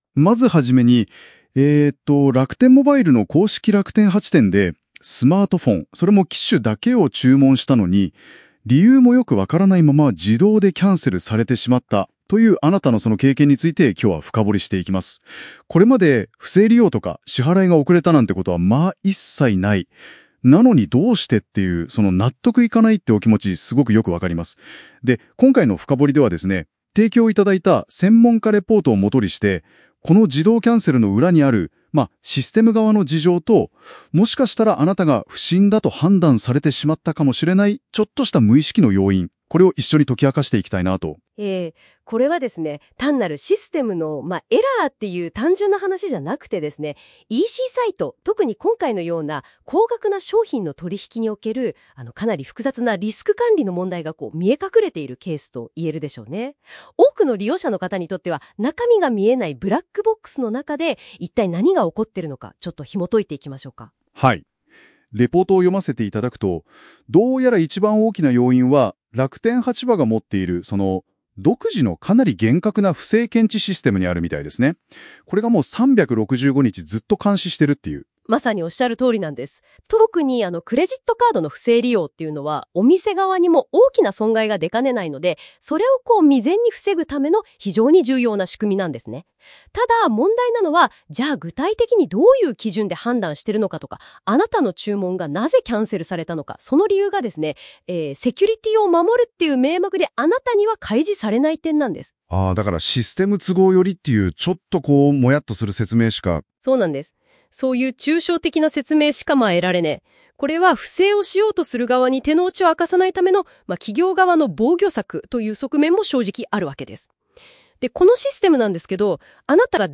Gemini;Audio Overviews(8:01min)
Gemini 生成の音声解説